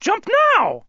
File File history File usage Metadata ElmoMusicDS_shortTestVox.ogg  (Ogg Vorbis sound file, length 0.9 s, 66 kbps) This file is an audio rip from a(n) Nintendo DS game.
ElmoMusicDS_shortTestVox.ogg.mp3